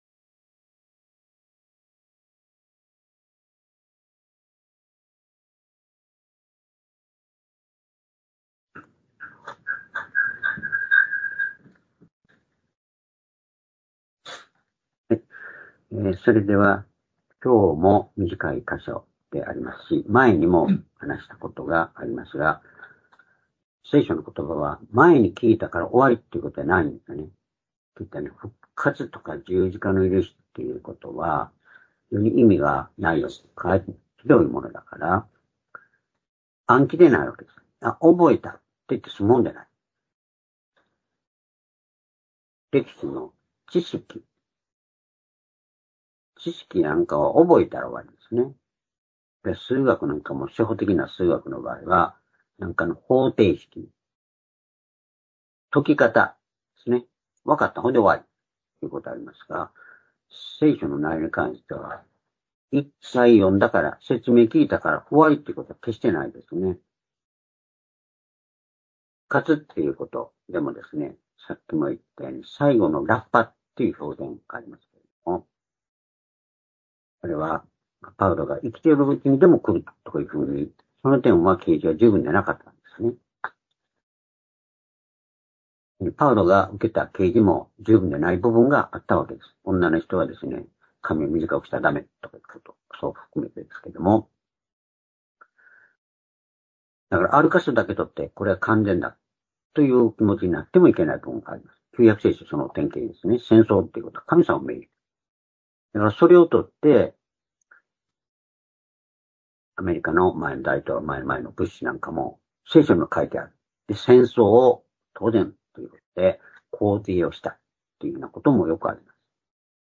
「どこから来て どこへ行くのか」-ヨハネ１９章６節～９節-２０２４年１１月２４日（主日礼拝）